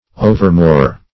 overmore - definition of overmore - synonyms, pronunciation, spelling from Free Dictionary Search Result for " overmore" : The Collaborative International Dictionary of English v.0.48: Overmore \O"ver*more"\, adv.